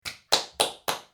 Hand Claps Single Var 2
SFX
yt_AOtV9fyidVs_hand_claps_single_var_2.mp3